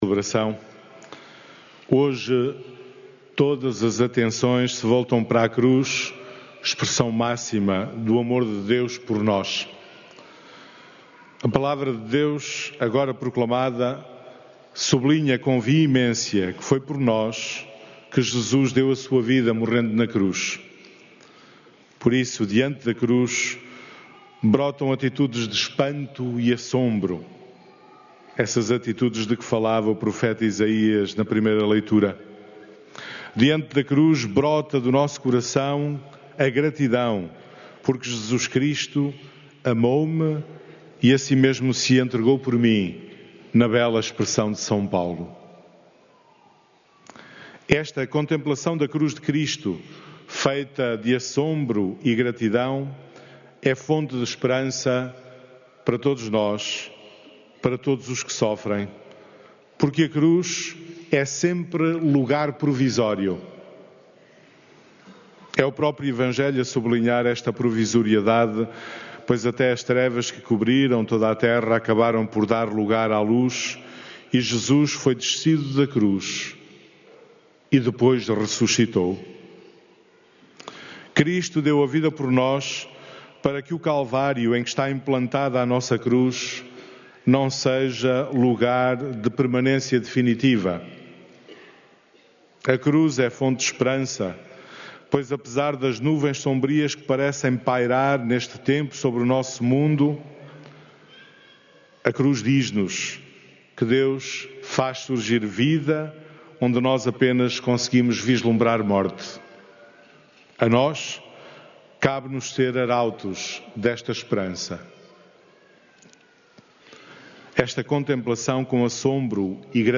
Áudio da homilia